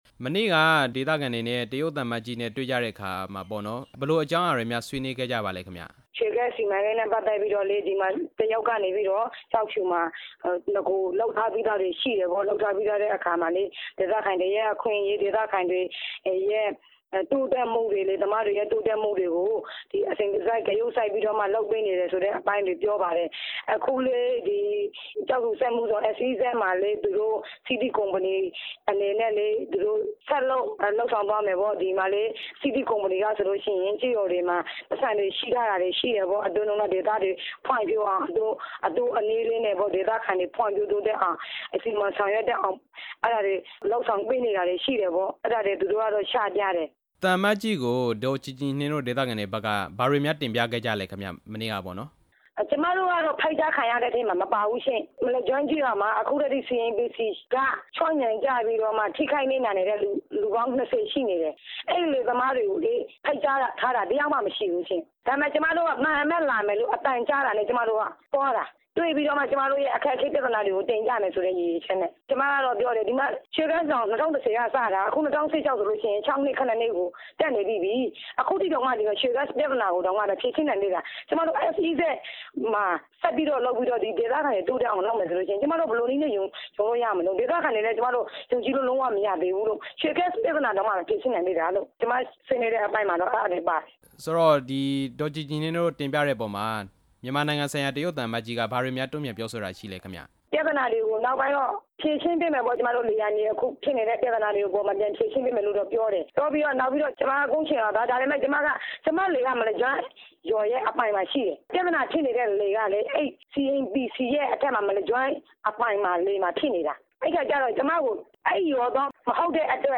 ဆက်သွယ်မေးမြန်းထား ပါတယ်။